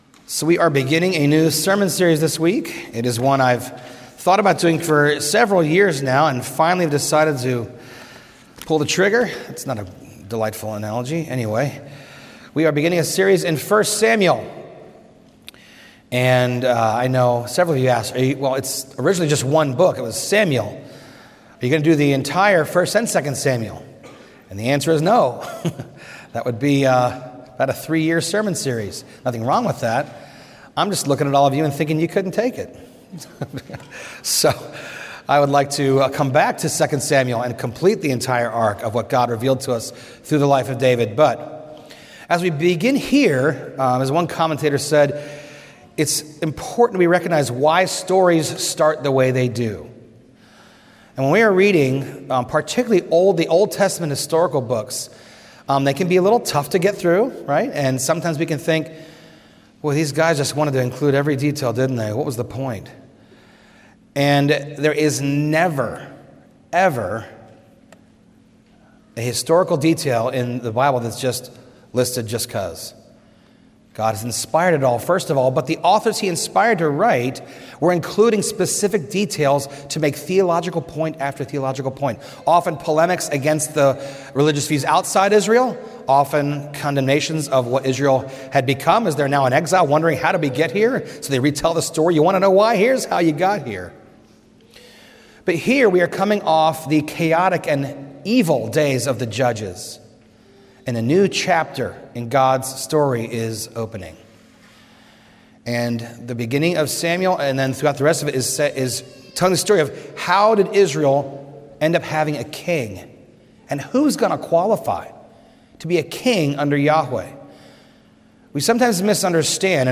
A message from the series "1 Samuel."